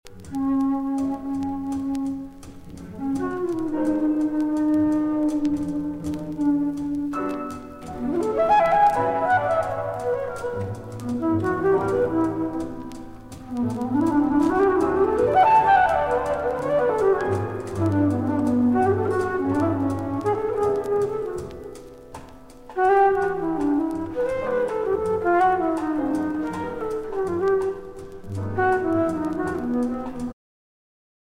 The guest musician was Lou Marini.
Jazz Band I; Marini, Lou (flute)